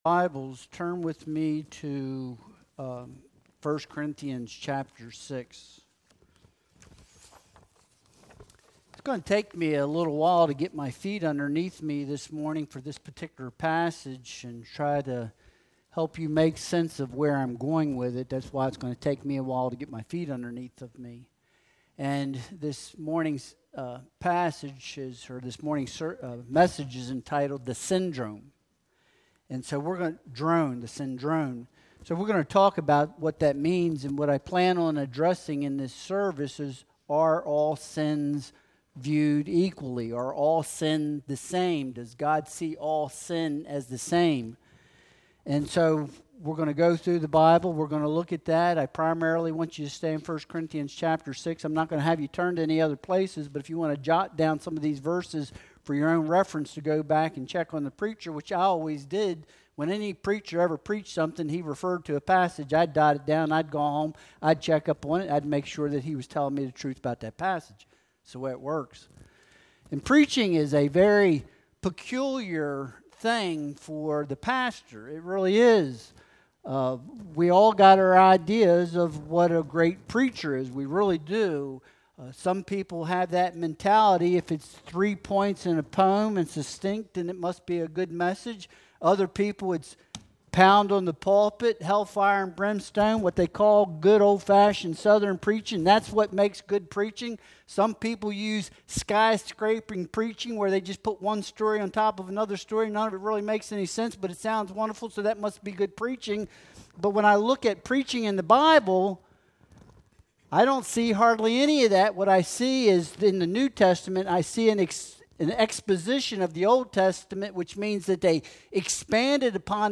Passage: 1 Corinthians 6.1-20 Service Type: Sunday Worship Service Download Files Bulletin Topics